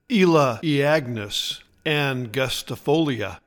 Pronounciation:
E-la-ee-AG-nus an-gus-ti-FO-lee-a